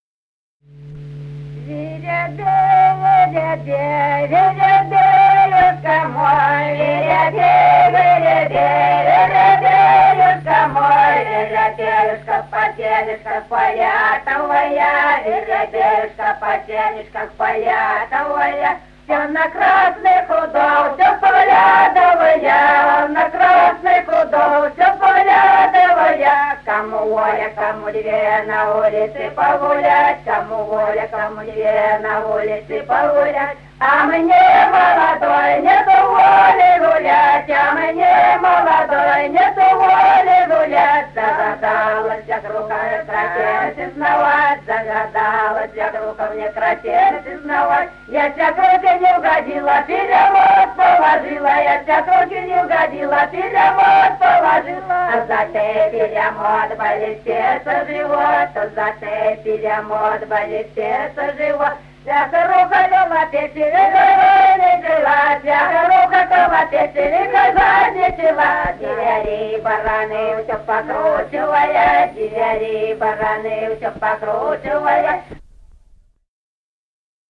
| filedescription = Воробей, воробей (игровая).
Песни села Остроглядово.